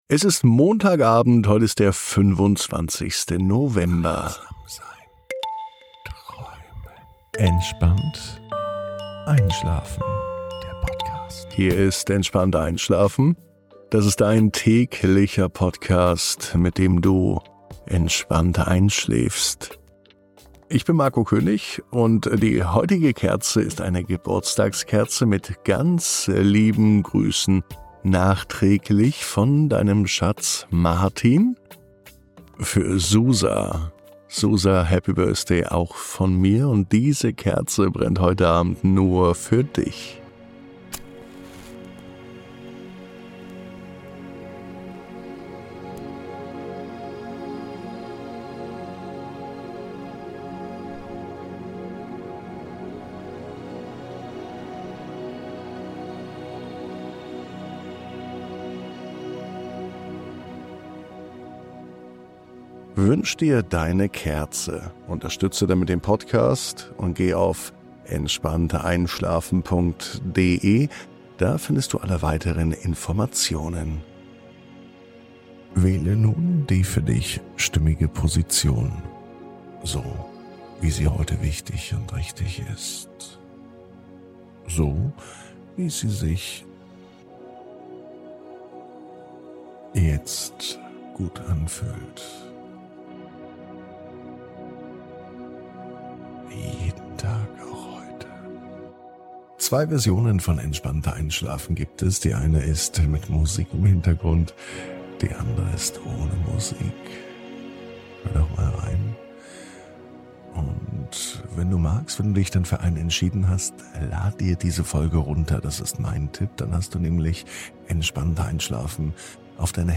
Zwei Versionen dieser Reise warten auf dich – mit oder ohne Musik, ganz wie es dir gefällt.